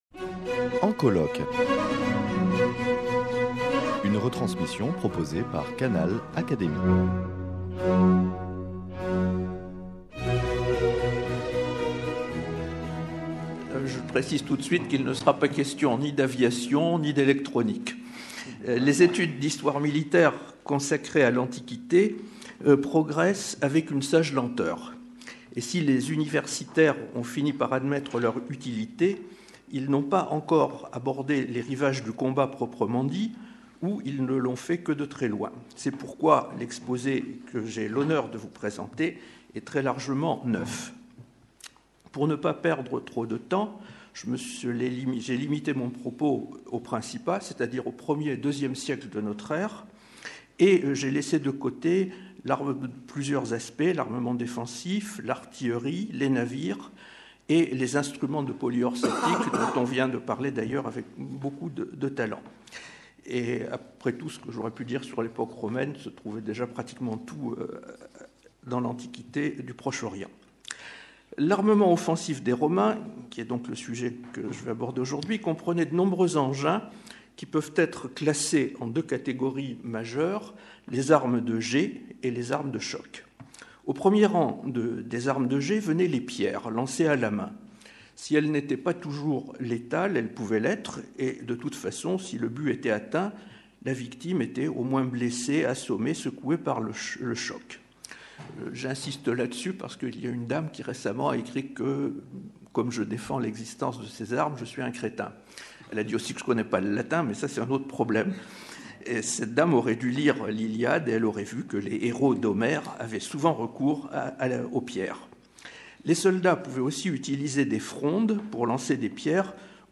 Journées d’étude « Guerre et technique » (1ère partie : Les armes)Communication